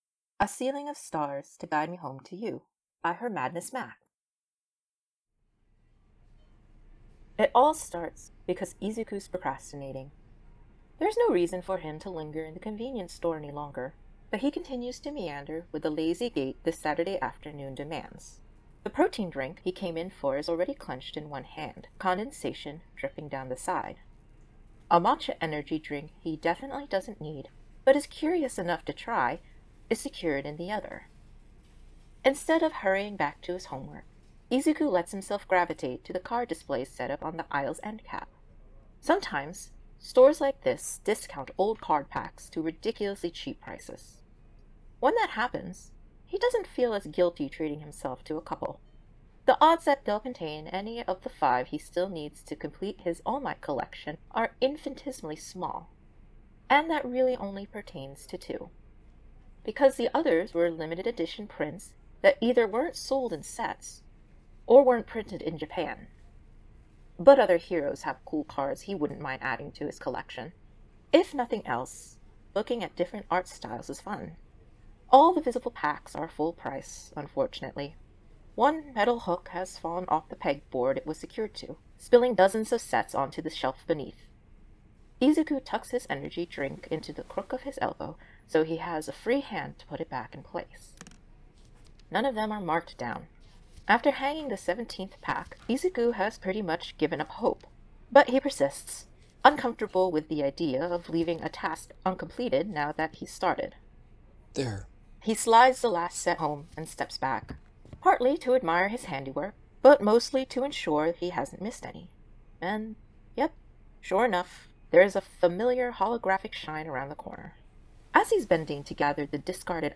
A Ceiling of Stars to Guide Me Home to You | Podfic | My Podfic Academia
Voice of Ochako Uraraka
Voice of Store Clerk [OC]
Voice of Tsuyu Asui